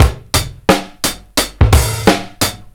Breakbeat